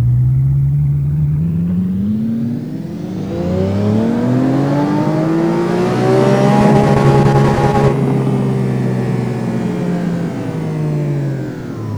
Index of /server/sound/vehicles/vcars/lamboaventadorsv
reverse.wav